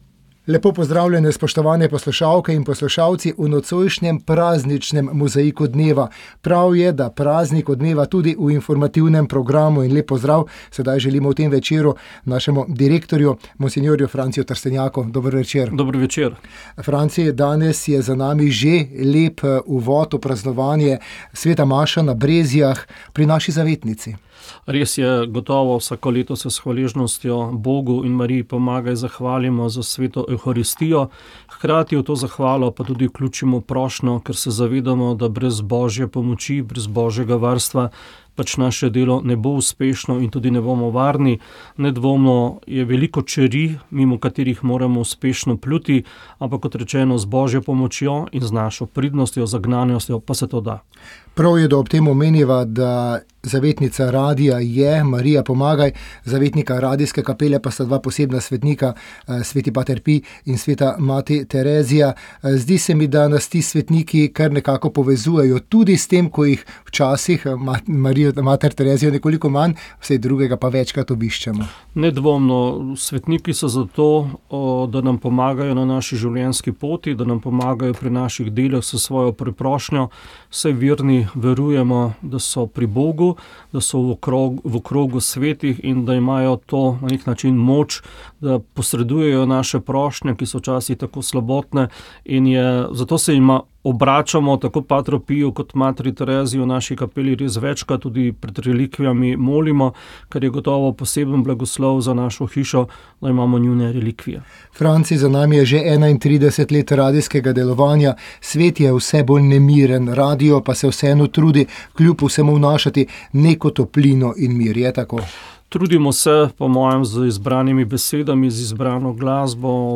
Da gre za precej pestro politično dogajanje v minulem tednu, je v pogovoru za naš radio opozorila predsednica nedavno ustanovljene stranke Naša dežela Aleksandra Pivec. Rešitev vidi v sodelovanju tako ene kot druge politične strani, predčasne volitve pa po njenih besedah sil v državnem zboru ne bi bistveno spremenile.